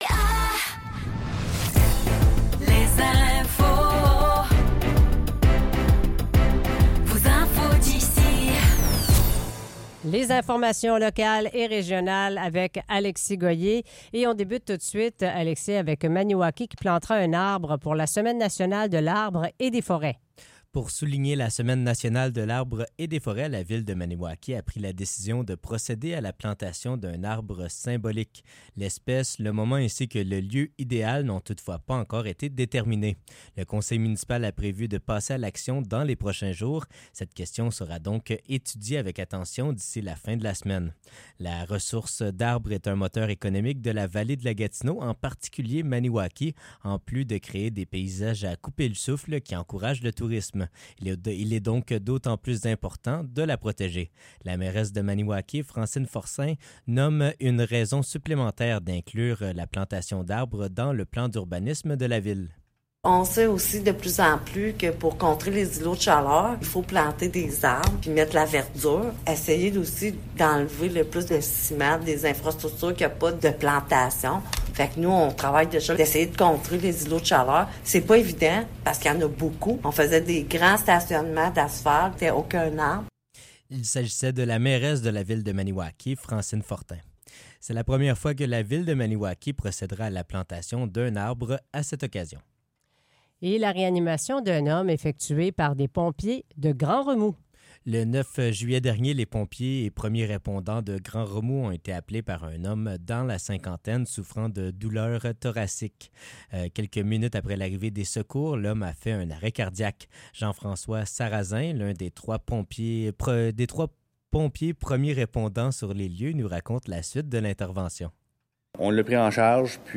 Nouvelles locales - 6 août 2024 - 15 h | CHGA